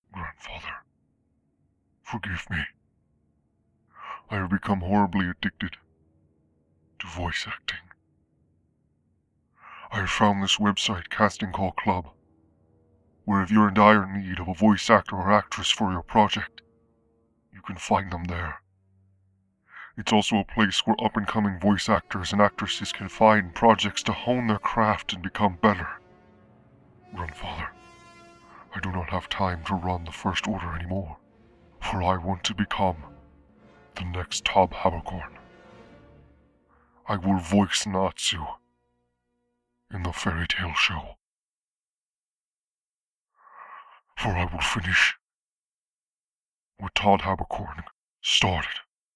Voice Actor
Joker
Kylo_ren_audition.mp3